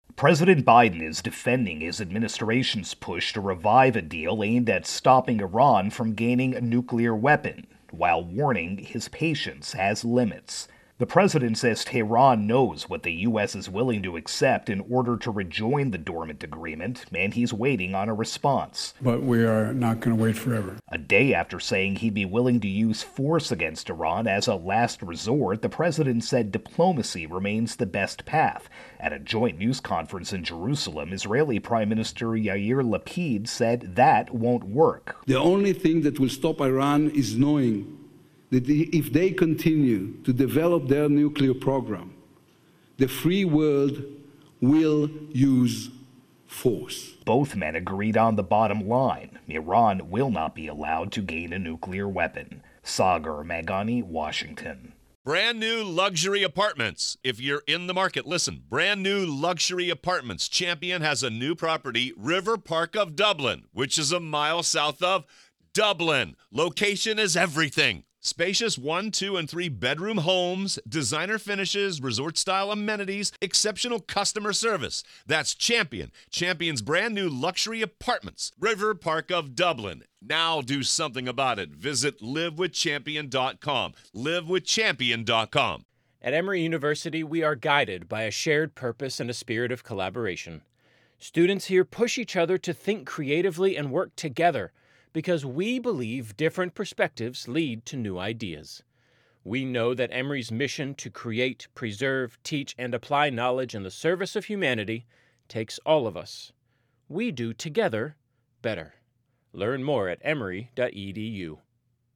reports on Biden-Mideast Wrap 141